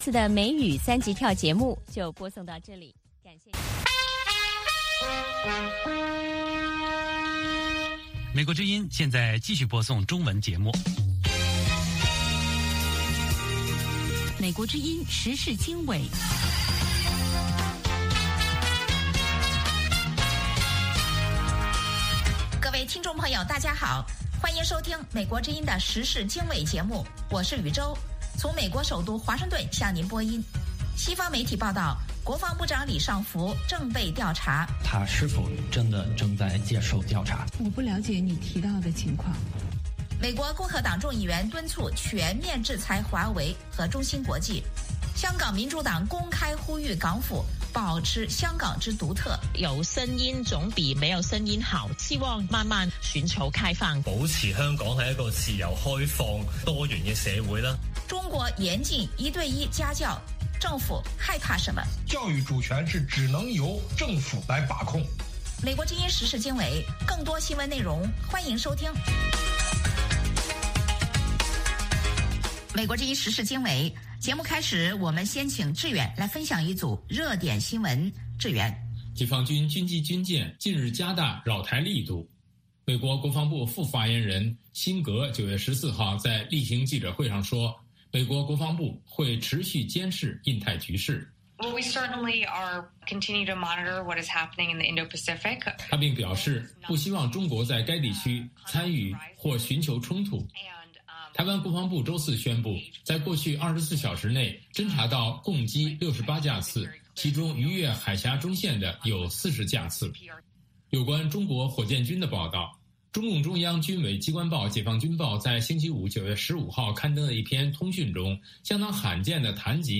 美国之音英语教学节目。